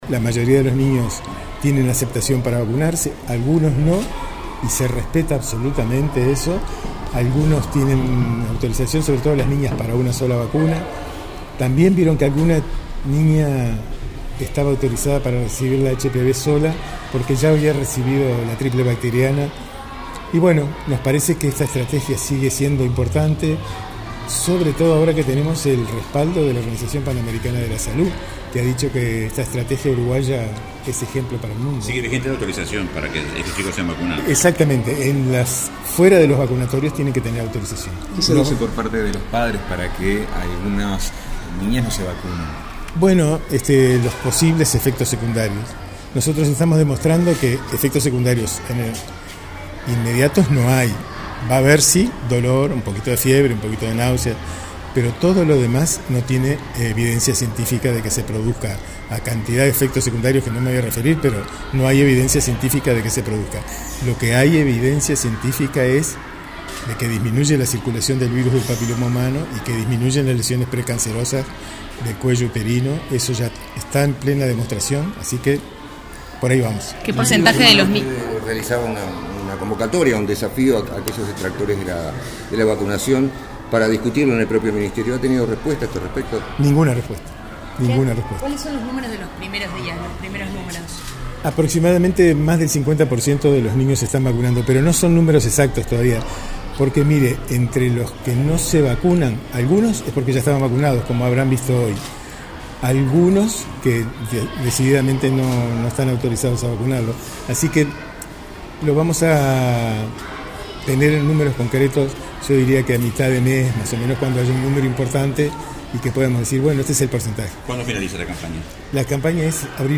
En el marco de la estrategia de vacunación de triple bacteriana y contra el HPV que lleva adelante el Ministerio de Salud Pública en alumnos de 6 ° año de primaria, autoridades asistieron a una jornada de vacunación en la escuela “Felipe Sanguinetti”.